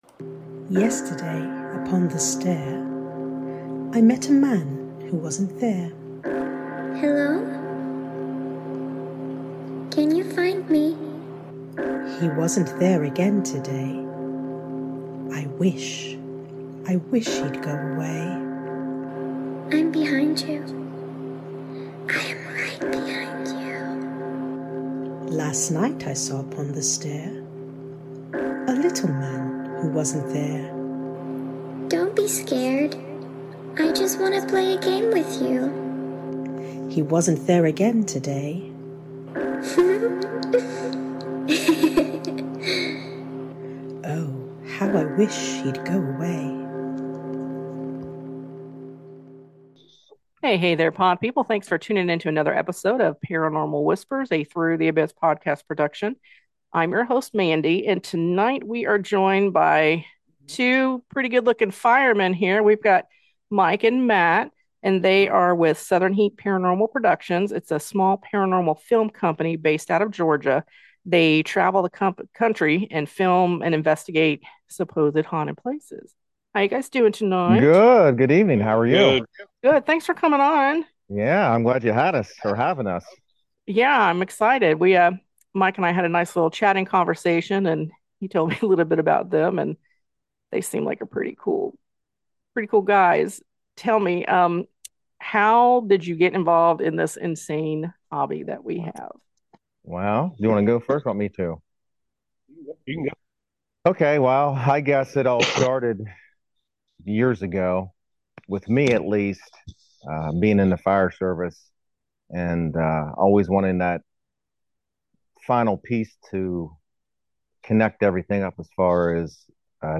MY CHAT WITH THE BOYS OF SOUTHRN HEAT PARANORMAL.